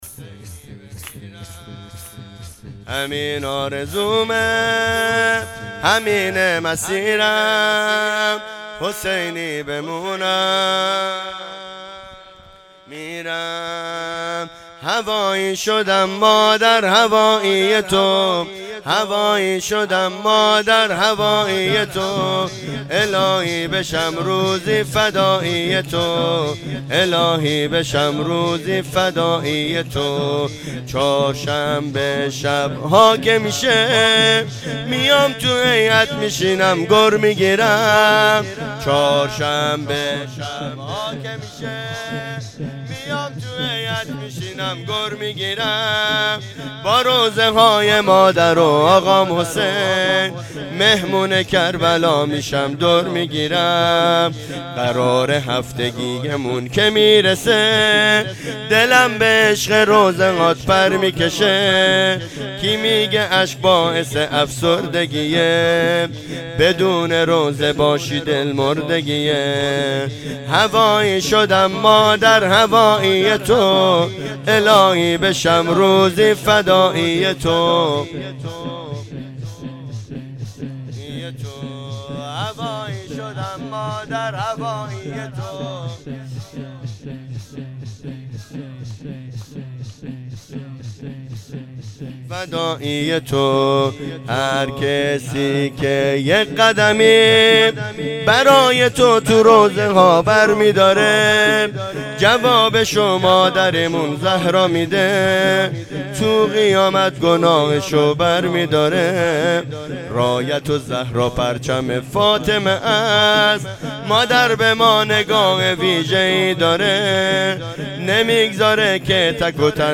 مراسم توسل به حضرت سید الشهدا (ع)1404